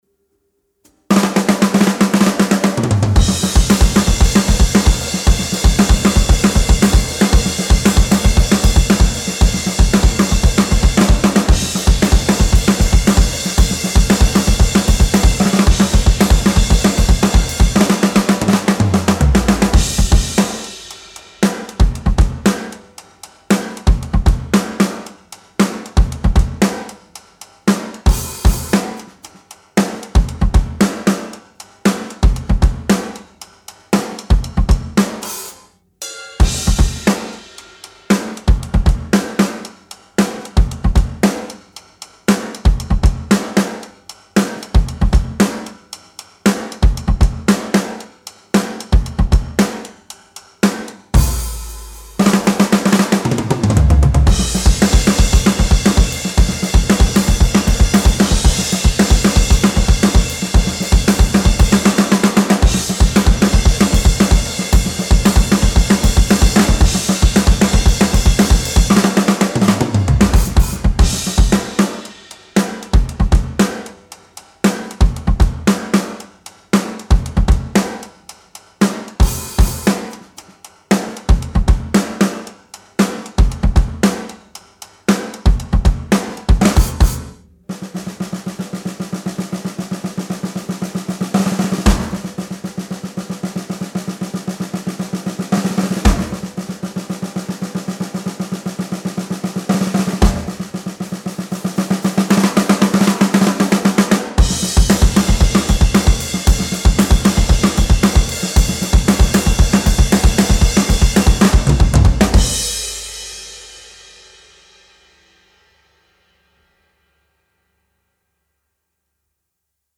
Genre:Rock, Metal
Tempo:230 BPM (4/4)
Kit:Rogers 1977 Big R 22"
Mics:14 channels